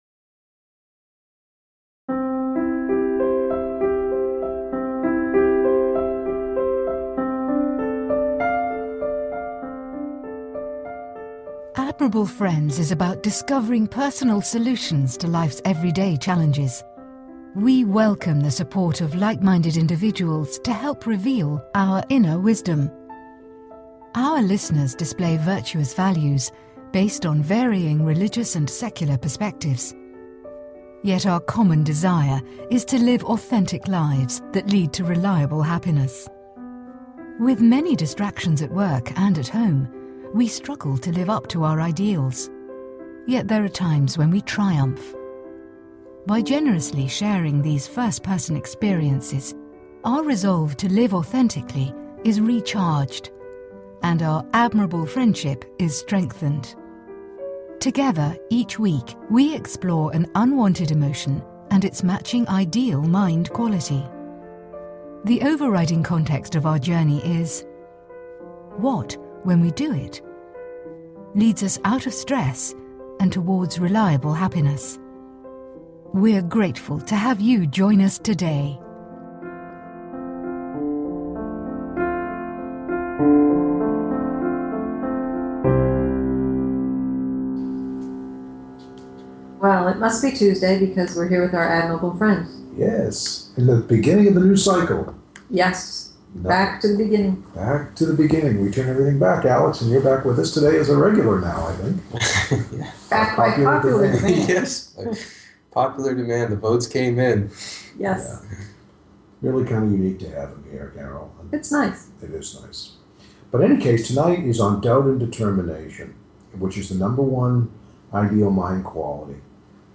(2) Guests are Interviewed on Anxiousness and Equanimity (Mental Calm)